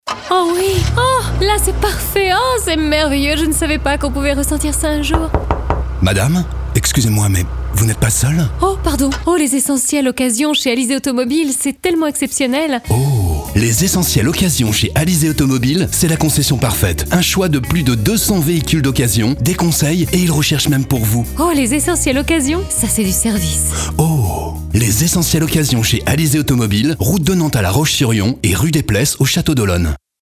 Nous pouvons créer pour vous les textes de vos spots publicitaires et réalisé ensuite l’enregistrement et le montage sur musique.
Alizés AutomobilesOh les Essentiels Occasions par Alizés Automobiles nous a donné des idées pour leur réaliser un spot unique. Création du texte, enregistrement deux voix et montage sur musique !